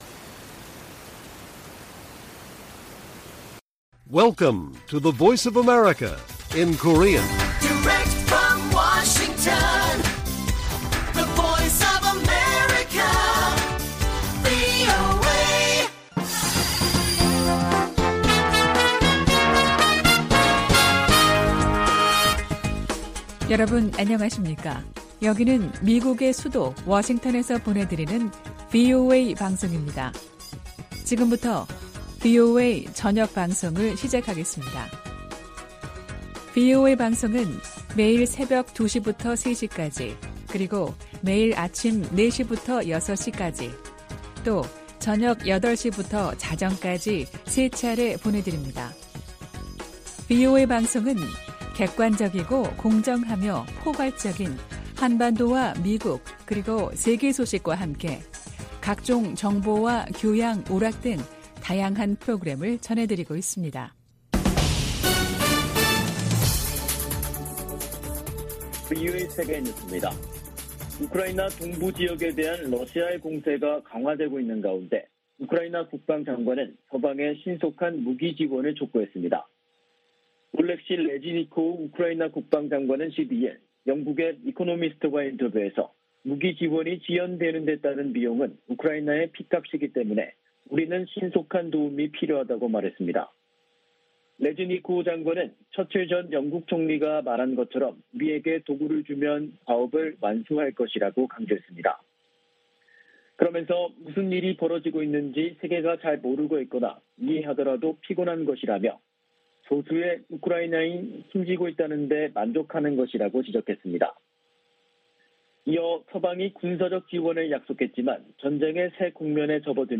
VOA 한국어 간판 뉴스 프로그램 '뉴스 투데이', 2022년 6월 13일 1부 방송입니다. 김정은 북한 국무위원장이 10일 "대적 강대강 정면투쟁"의 강경기조를 천명한데 이어, 12일 북한이 방사포 무력시위를 벌였습니다. 미국과 일본, 호주 국방장관들이 북한의 핵무기 개발과 거듭된 미사일 발사를 강력 비판했습니다. 영국 국제전략문제연구소(IISS)는 미국과 한국의 대북 영향력은 갈수록 줄어드는 반면 중국의 영향력을 커지고 있다고 진단했습니다.